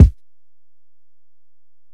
Kick (24).wav